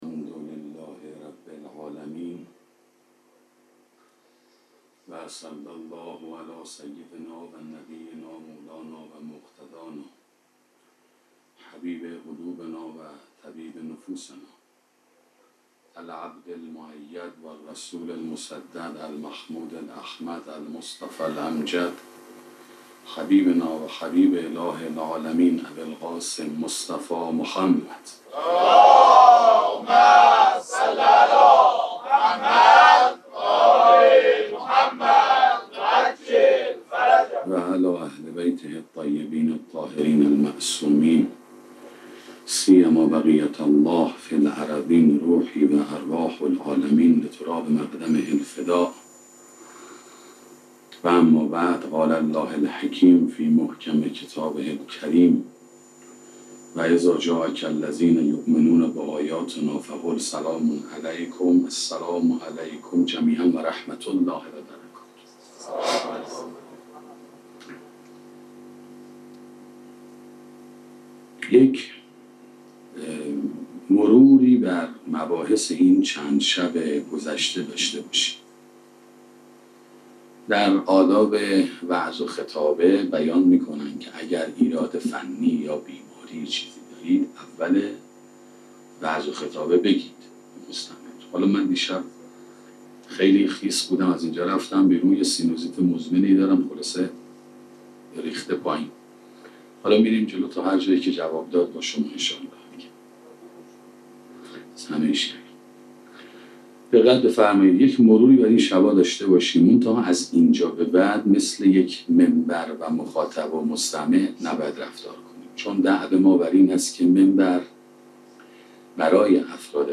سخنرانی جایگاه معنوی مادر 1